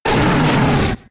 Cri d'Arcanin K.O. dans Pokémon X et Y.